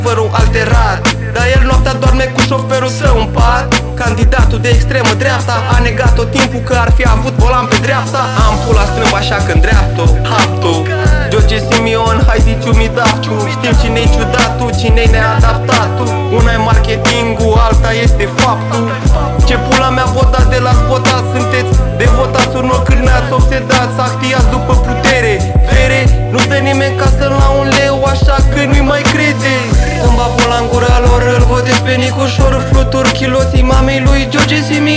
Underground Rap Hip-Hop Rap
Жанр: Хип-Хоп / Рэп